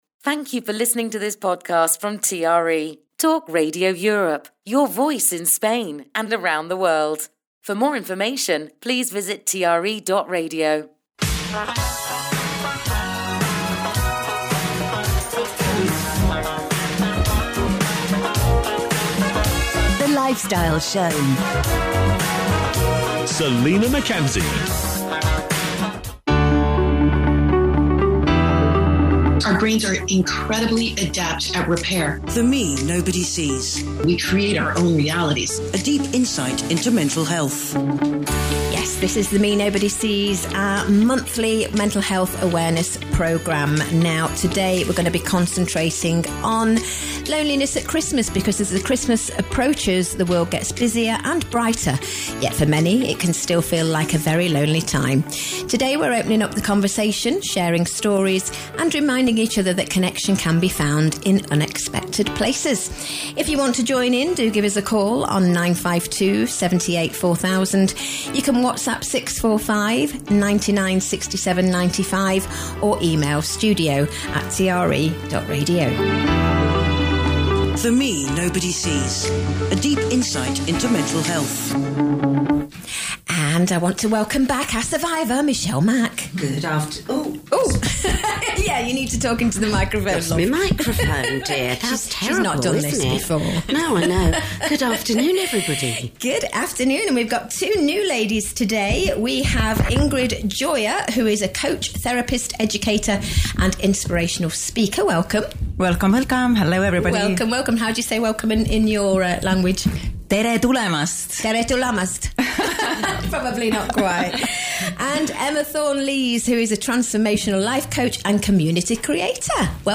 I recently appeared on a radio show where we spoke about exactly this – about loss, transition and inner resilience.